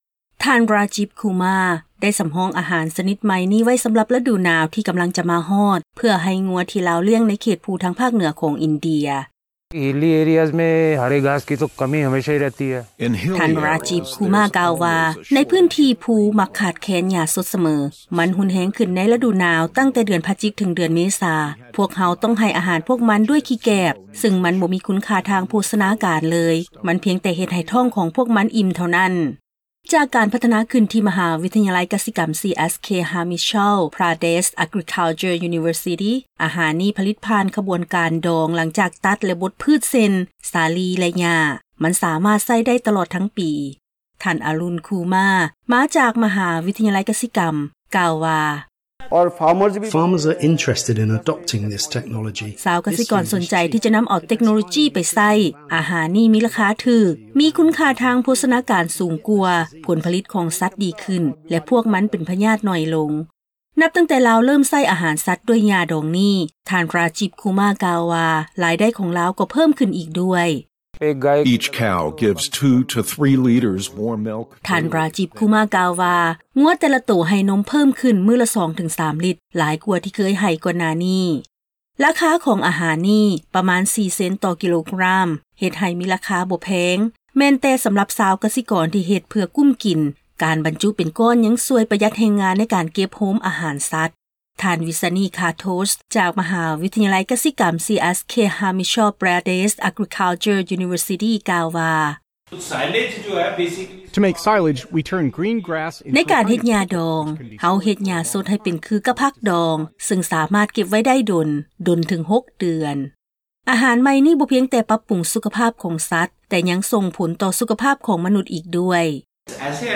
ວີດີໂອລາຍງານ ກ່ຽວກັບອາຫານຊະນິດໃໝ່ສໍາລັບຊາວກະສິກອນໃນພາກເໜືອຂອງອິນເດຍ.